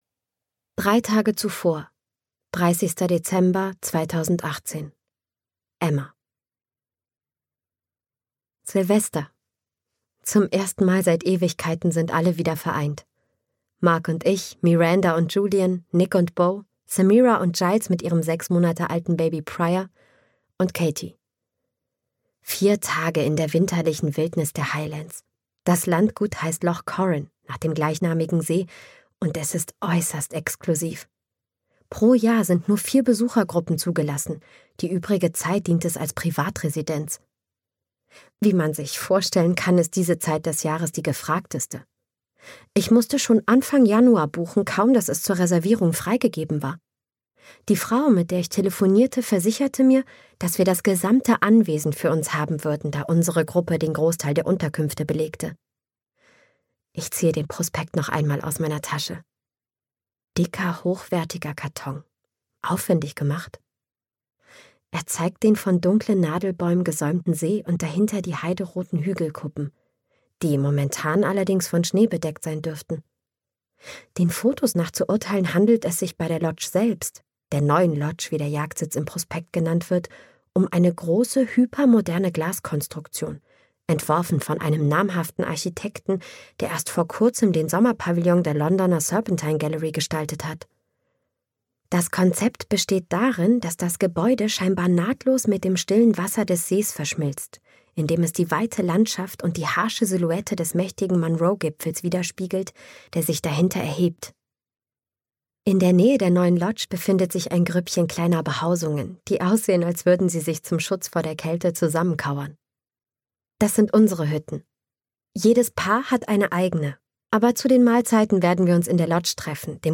Neuschnee (DE) audiokniha
Hörbuch Neuschnee von Lucy Foley.
Ukázka z knihy